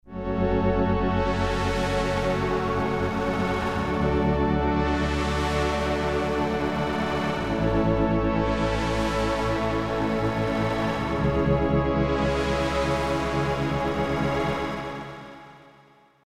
REVIEW "Fun unit with very lo fidelity sounds. Some very good textures out of PCM and FM syntheses."
pad.mp3